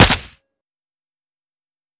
Index of /fastdl/sound/weapons/ak103
fire.wav